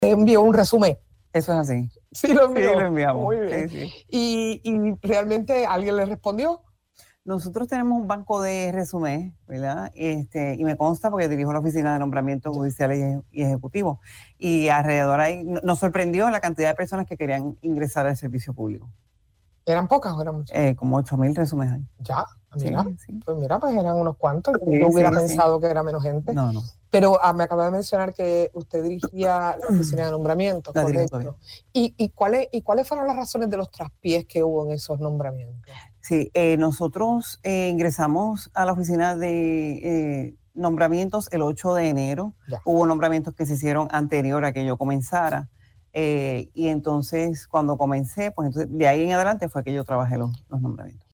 La designada secretaria del Departamento de Justicia, Lourdes Lynnette Gómez Torres, aseguró en entrevista para Radio Isla que actuará conforme a la ley y el derecho en situaciones que traten el tema del aborto.